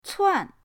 cuan4.mp3